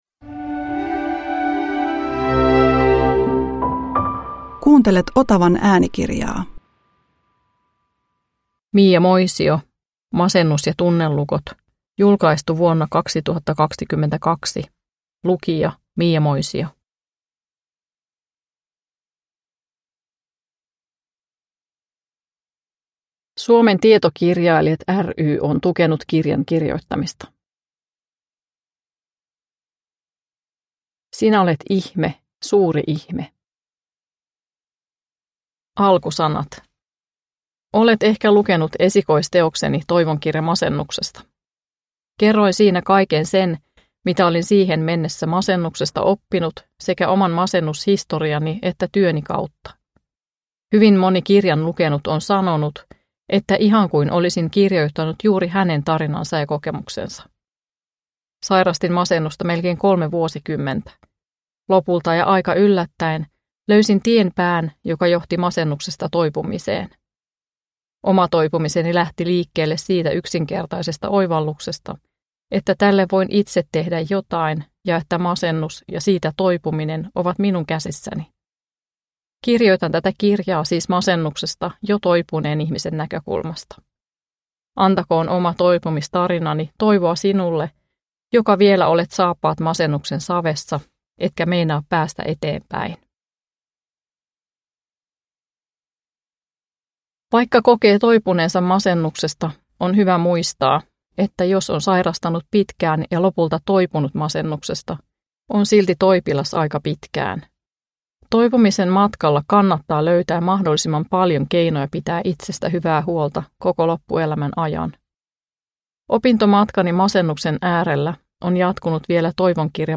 Masennus ja tunnelukot – Ljudbok – Laddas ner